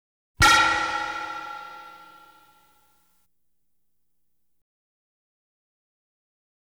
Light Beam Hit Sound Effect
Download a high-quality light beam hit sound effect.
light-beam-hit-5.wav